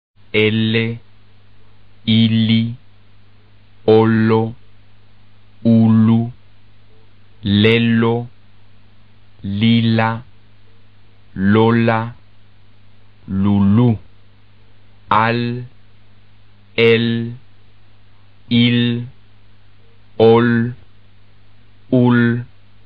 音标是[l]，这是一个舌尖齿龈边擦浊辅音，也就是在发音时，舌尖接触上齿龈，舌面下降，气流从舌面两侧通过。
【边音发音】